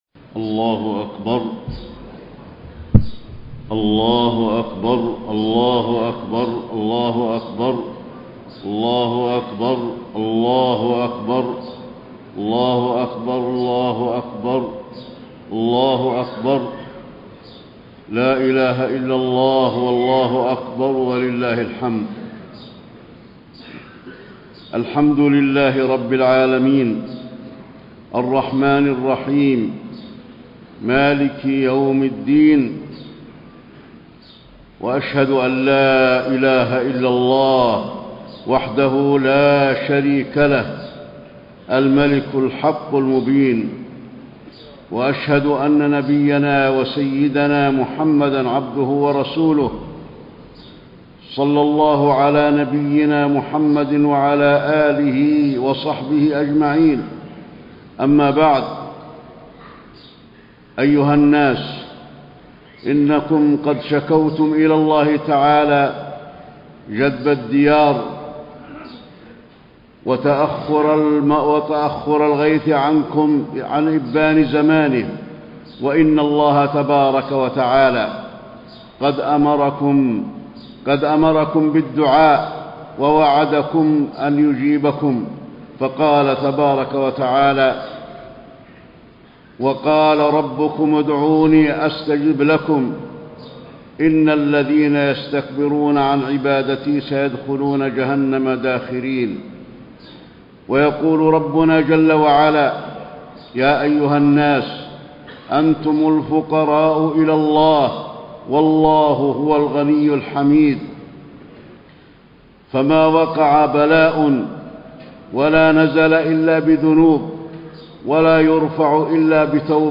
خطبة الاستسقاء - المدينة- الشيخ علي الحذيفي - الموقع الرسمي لرئاسة الشؤون الدينية بالمسجد النبوي والمسجد الحرام
المكان: المسجد النبوي